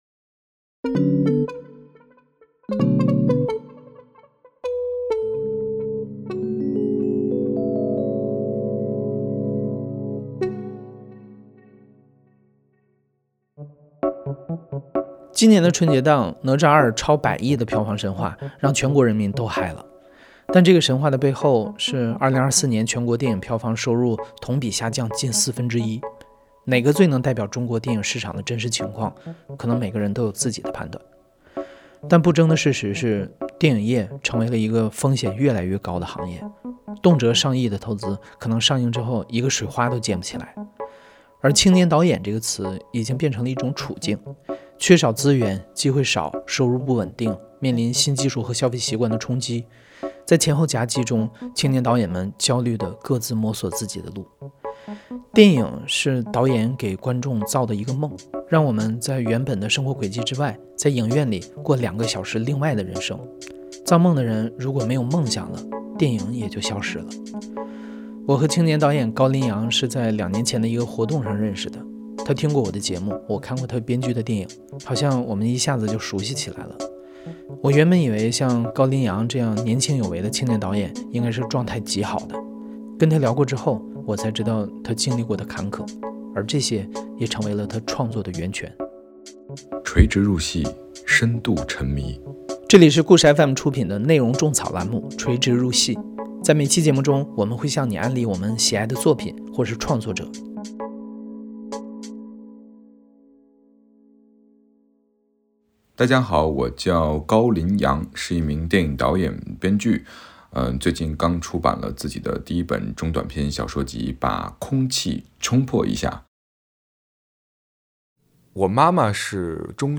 故事FM 是一档亲历者自述的声音节目。